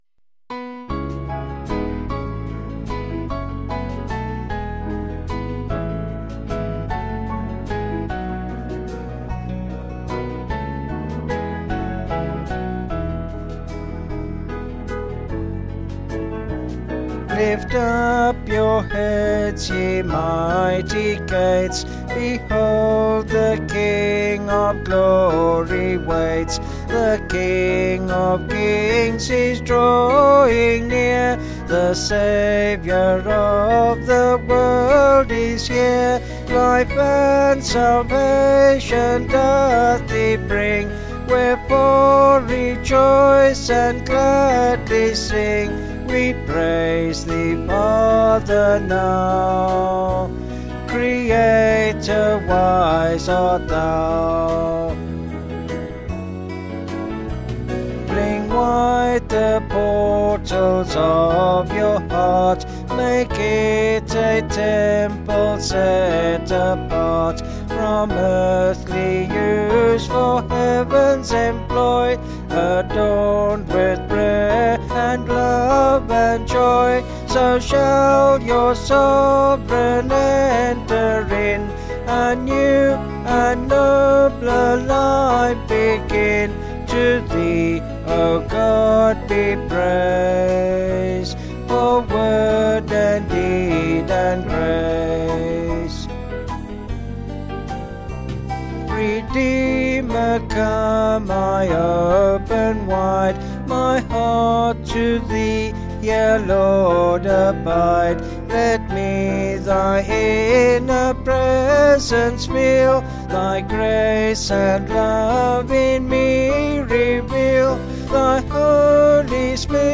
Vocals and Band   701.8kb Sung Lyrics